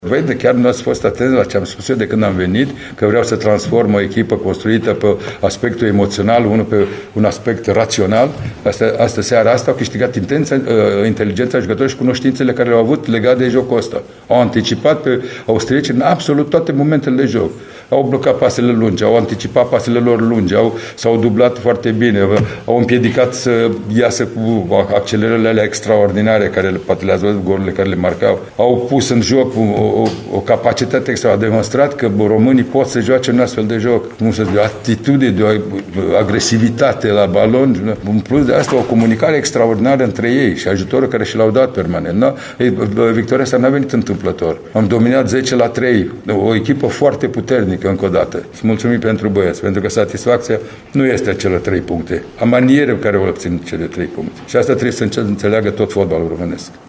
Ursuz cum ne-a obișnuit, Lucescu a explicat că mai importantă decât punctele obținute e maniera rațională în care a fost învinsă Austria: